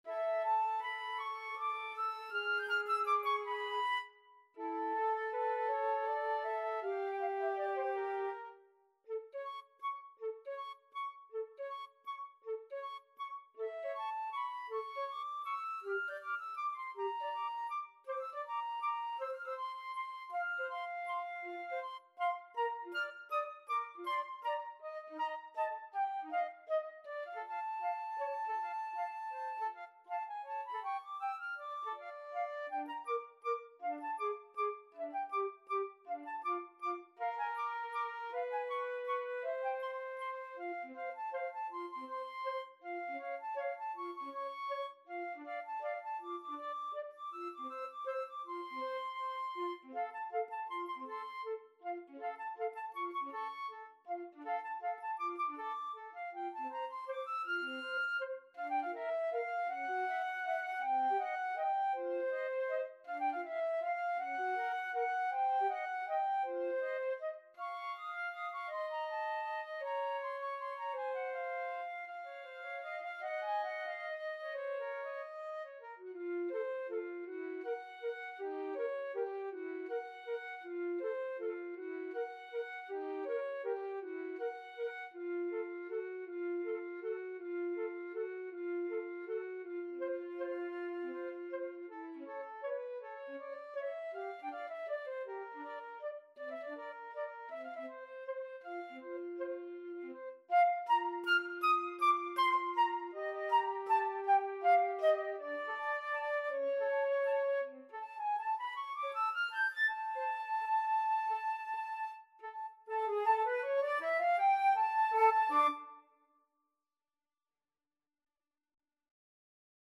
Allegretto = 160
3/4 (View more 3/4 Music)
Classical (View more Classical Flute Duet Music)